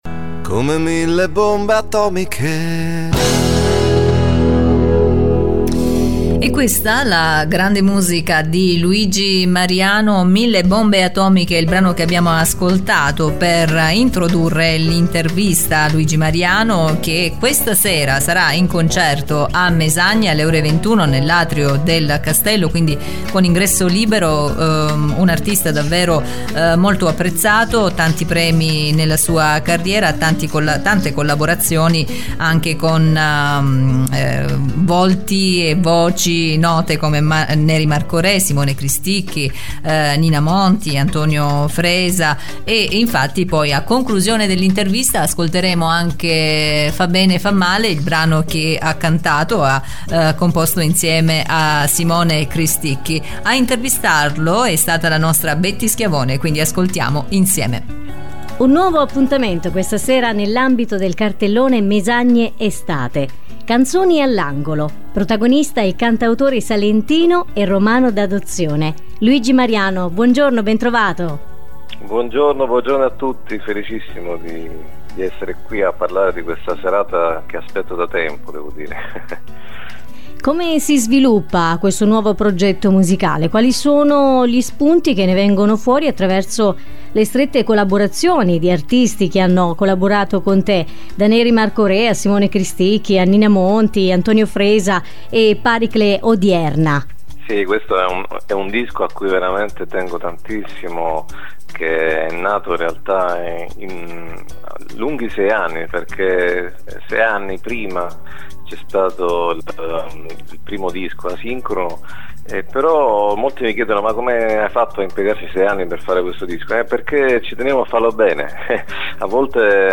Intervista di presentazione a “IDEA Radio“